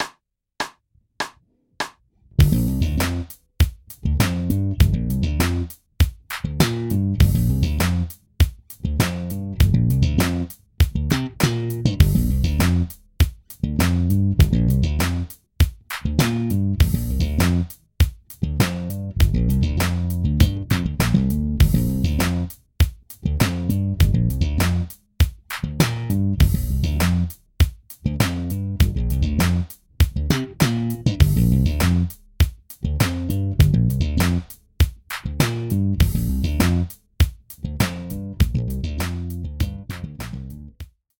Groove Construction 3 Bass Groove Construction 3